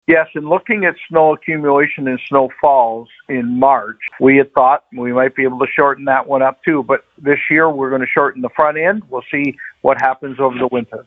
Mayor Joe Preston advised, for this winter, it is a bit of a pilot project.
nov-14-joe-preston-overnight-parking-on-air-2.mp3